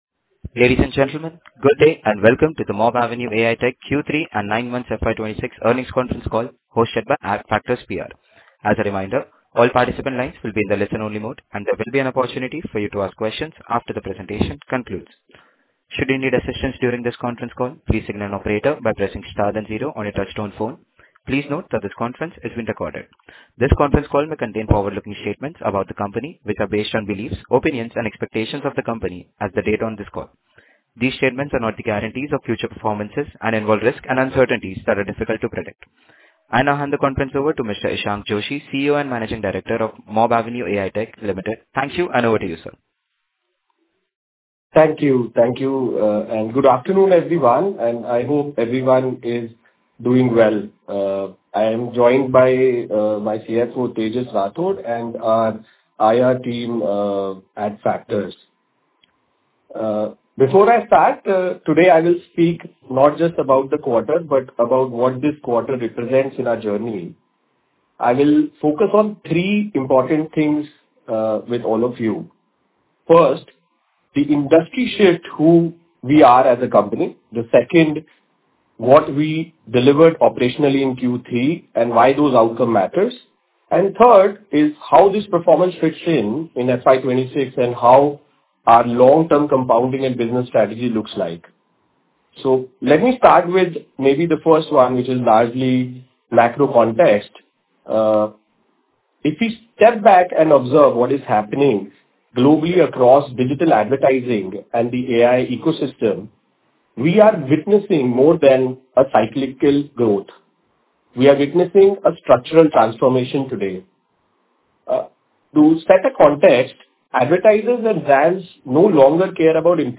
Audio_Recording_Earnings_Call_Dec_2025.mp3